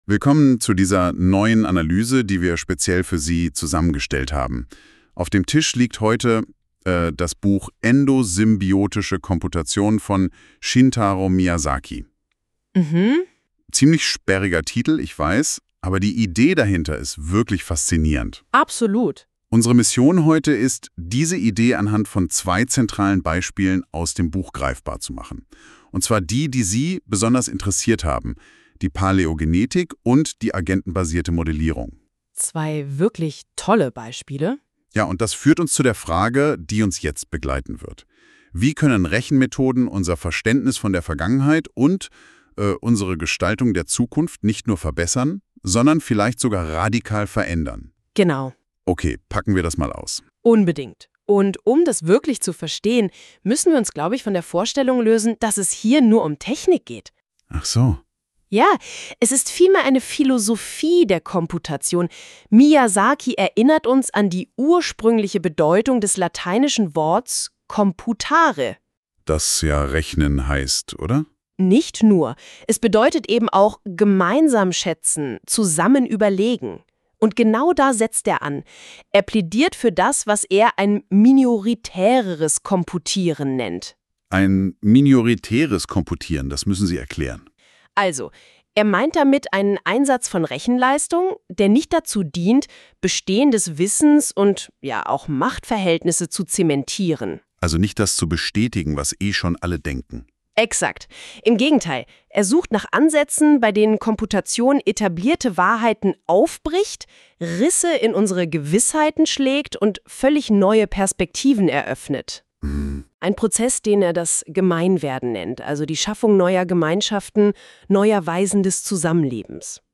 Endosymbiotische Komputation – eine maschinengenerierte Buchbesprechung 3 ~ Endosymbiotische Komputation Podcast
Beschreibung vor 4 Monaten Endosymbiotische Komputation – eine maschinengenerierte Buchbesprechung (dieses Mal mit Fokus die Abschnitte über Paläogenetik und agenetenbasierte Modellierung für minoritäres Komputieren). Die kleinen Glitches in der Aussprache etc. wirken selbstreflektiv auf die Produktionsbedingungen des Gesprächs.